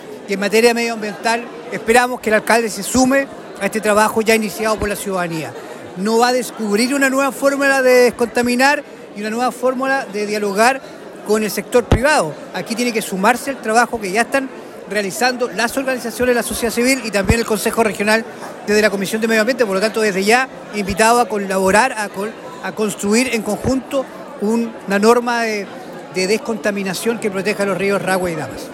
El pasado viernes, diversas autoridades nacionales y regionales participaron en la ceremonia de instalación del nuevo gobierno comunal de Osorno, encabezado por el Alcalde Jaime Bertín y su Concejo Municipal.